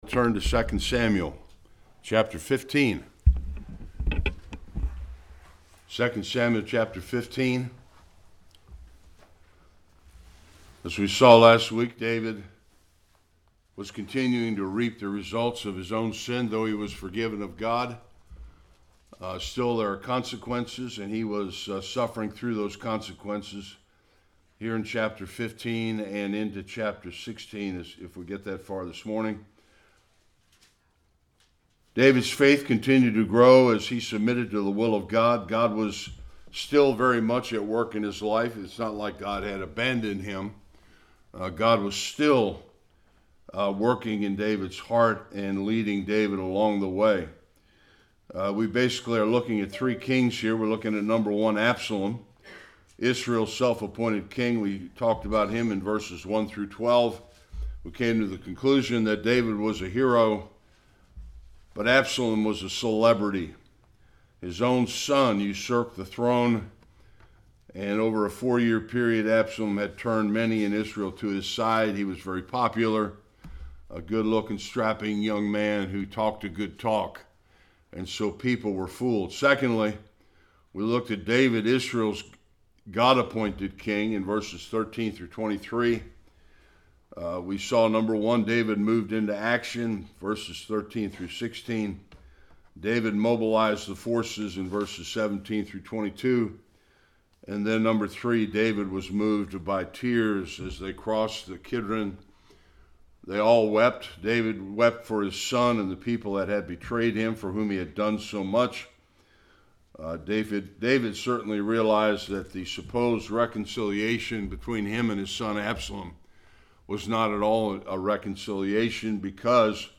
1-4 Service Type: Sunday School Absalom